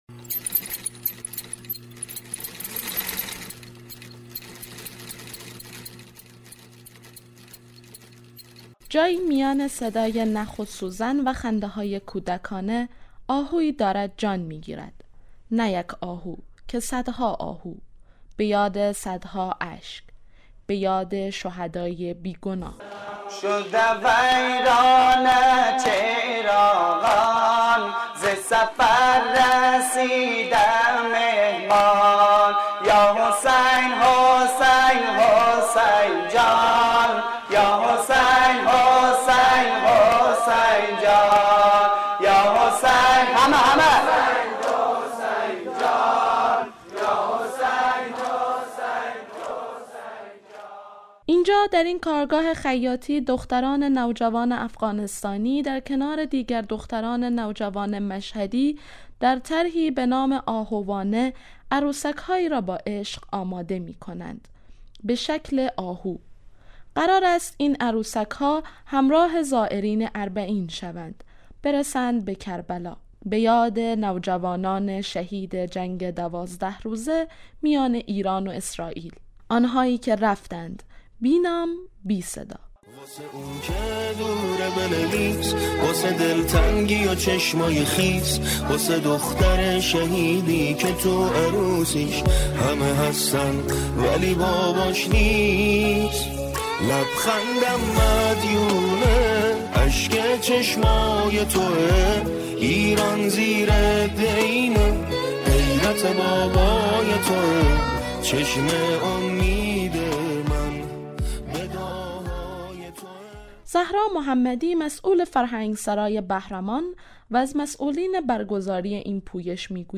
مستند گزارشی